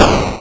npc_explosion.wav